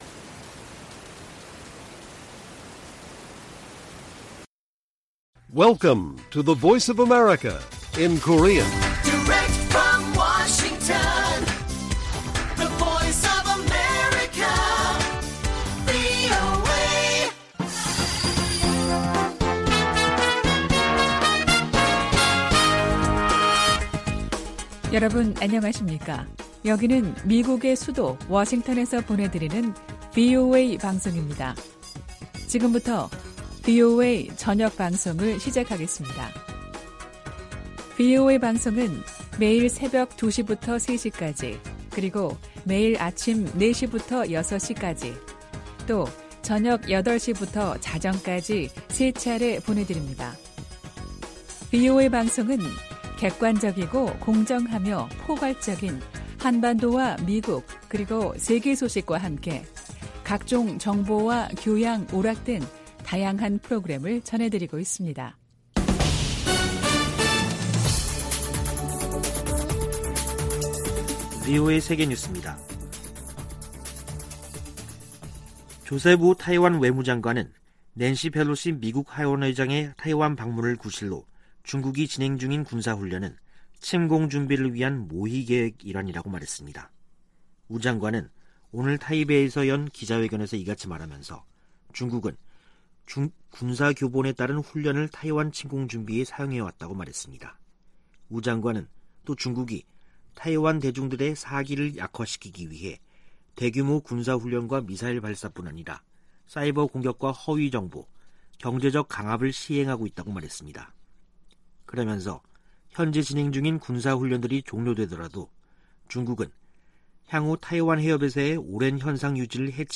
VOA 한국어 간판 뉴스 프로그램 '뉴스 투데이', 2022년 8월 9일 1부 방송입니다. 미국 정부가 북한 해킹조직 라자루스가 탈취한 가상화폐의 세탁을 도운 믹서 업체를 제재했습니다. 국무부는 우크라이나 친러시아 세력의 독립인정을 강력히 규탄하며 북한 노동자 파견은 대북 제재 위반이라는 점을 분명히 했습니다. 미 하원의원들이 베트남전쟁에 미군과 함께 참전했던 미국 내 한인들에게 의료 혜택을 제공하는 입법을 촉구했습니다.